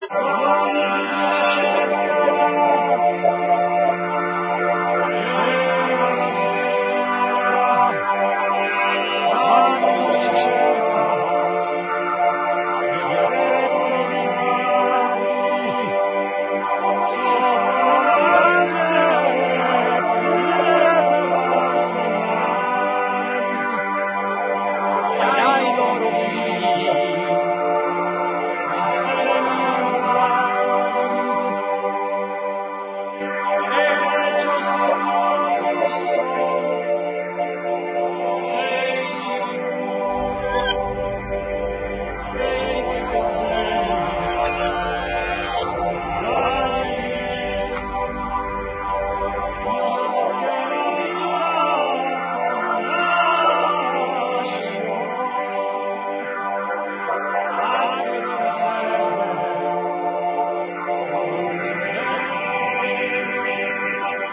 messaggio rovesciato